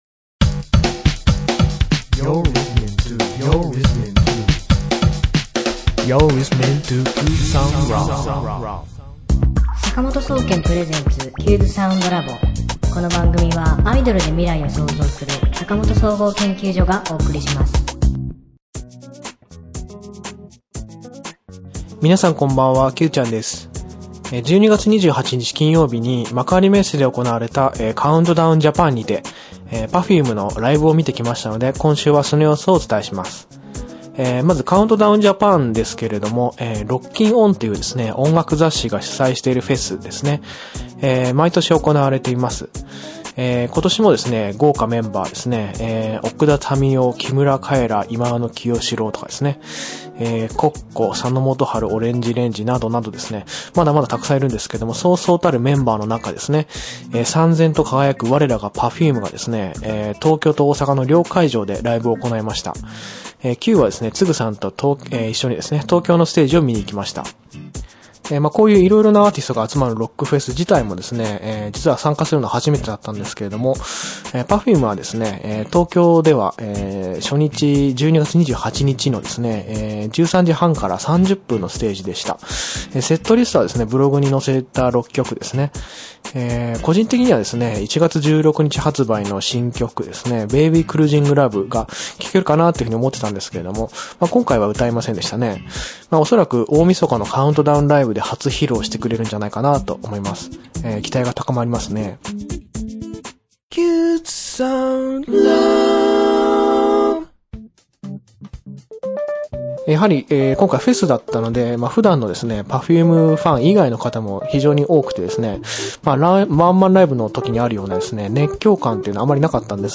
挿入曲：讃美歌106番『あら野のはてに』
作曲・編曲・コーラス・歌：坂本総合研究所